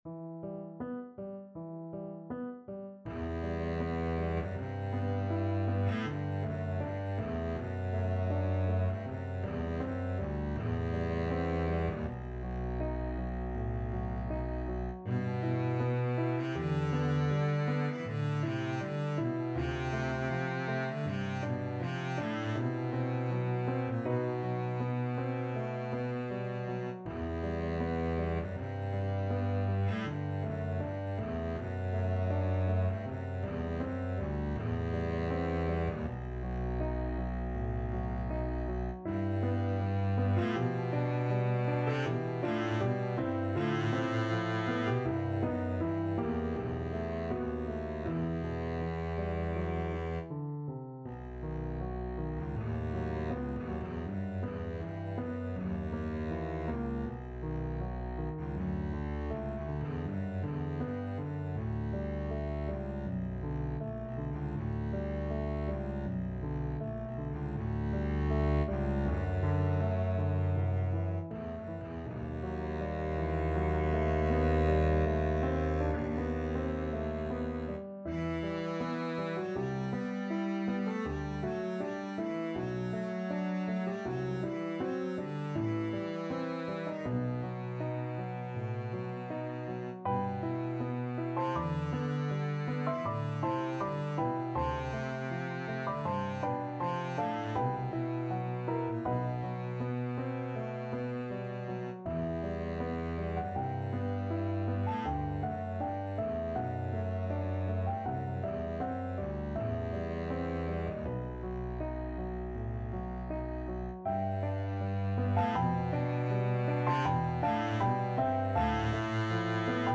Free Sheet music for Double Bass
Double Bass
The pavan was a sixteenth- and seventeenth-century court dance of Italian origin, in duple meter, and stately in tempo and spirit.
E minor (Sounding Pitch) (View more E minor Music for Double Bass )
4/4 (View more 4/4 Music)
Andante
Bb2-A4
Classical (View more Classical Double Bass Music)